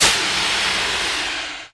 Airlock Door Open, Burst Of Low Steam With Slow Hiss Fade Out